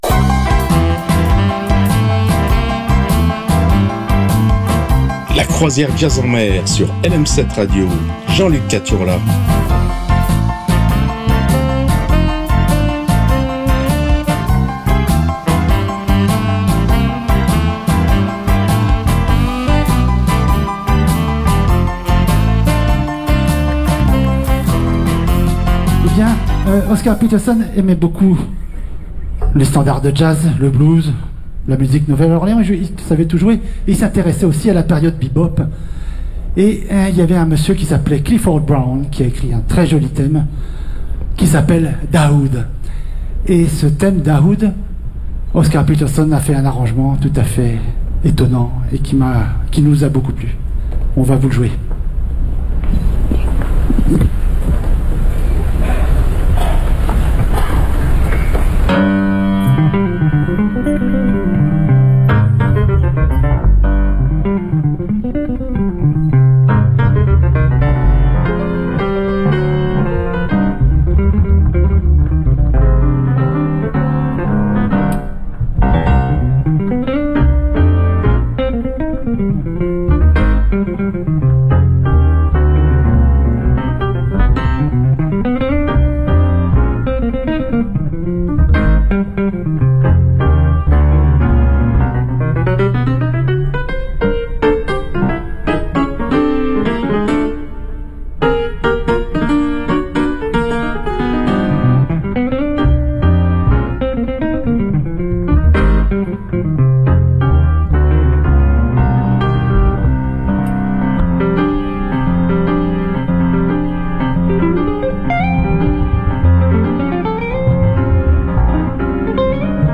passer une demi-heure sur des rythmes jazzy